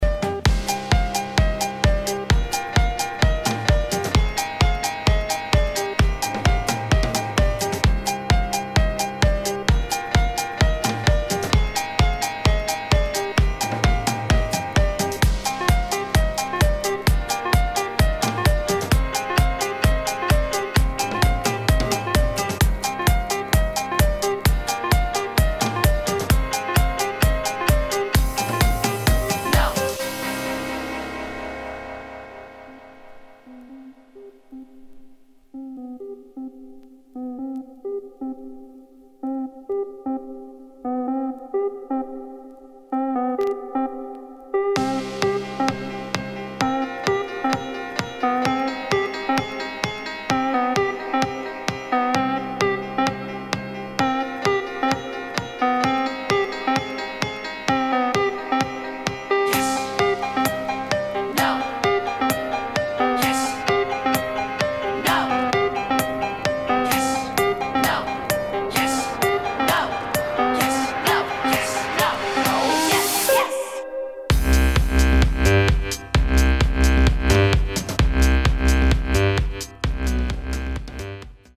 HOUSE | ELECTRO